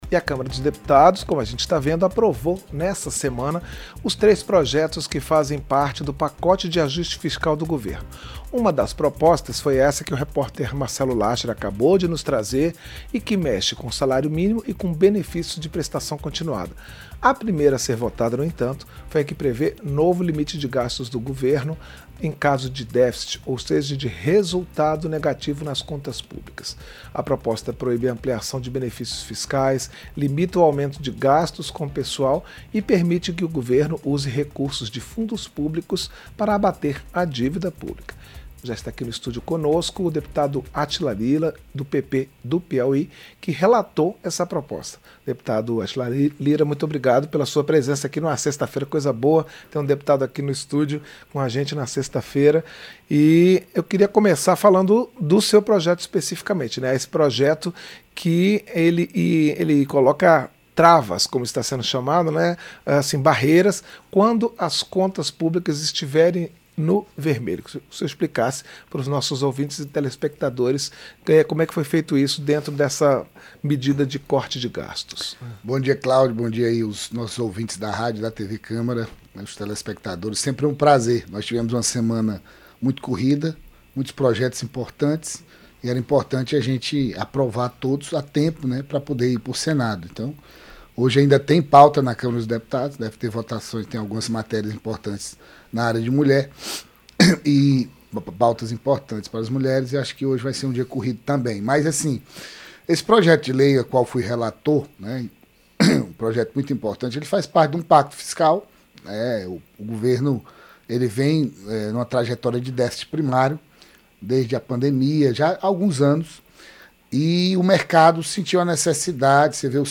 Entrevista – Dep. Átila Lira (PP-PI)